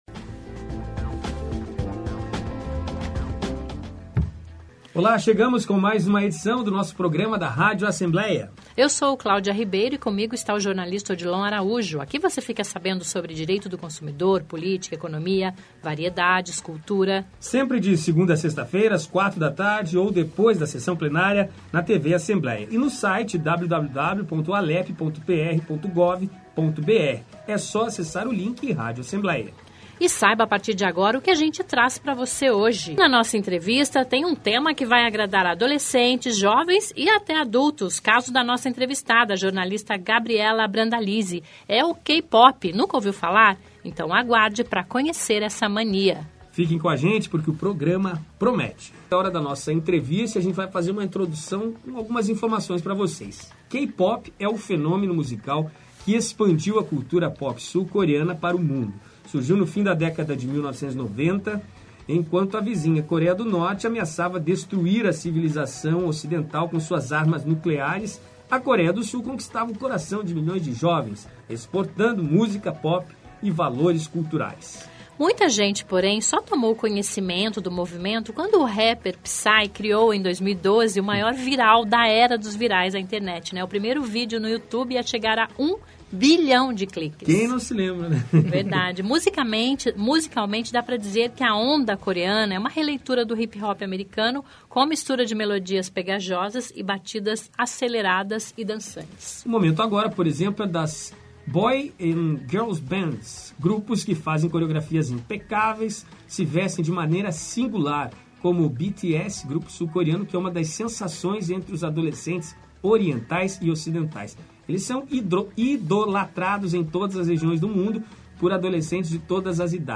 K-Pop, Dorama, ..Descubra o que é isso na nossa entrevista desta quarta (19)